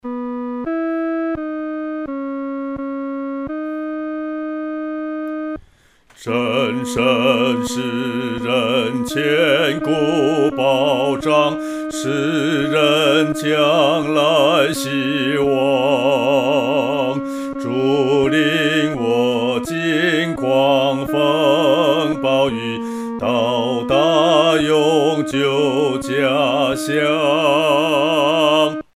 独唱（第二声）